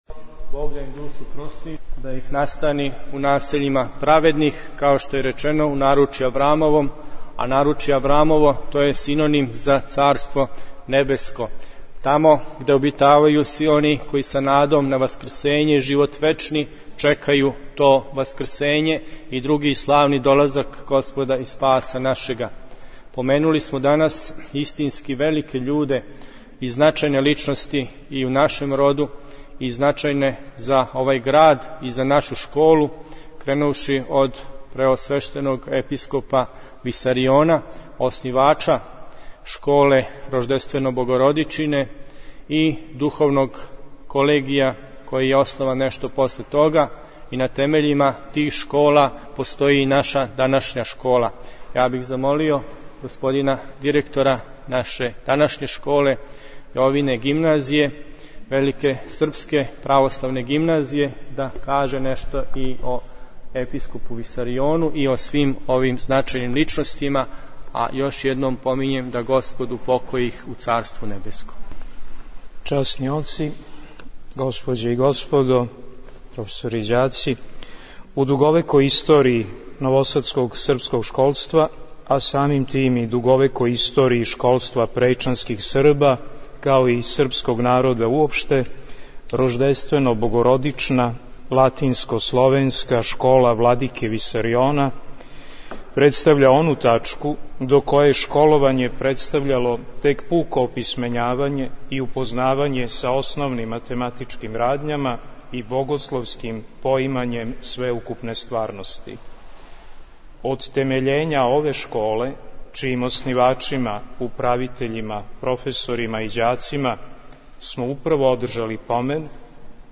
Професори и ученици Змај Јовине Гимназије у Новом Саду, данас, 22. септембра 2010. године, у новосадском Саборном храму молитвено су се сетили оснивачâ и добротворâ знамените Висарионове школе која је била славни претеча данашње гимназије.